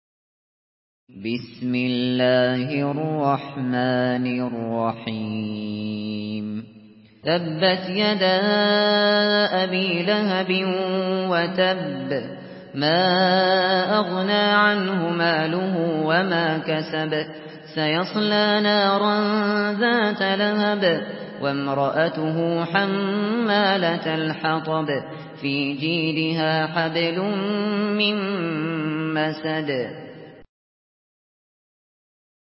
سورة المسد MP3 بصوت أبو بكر الشاطري برواية حفص
مرتل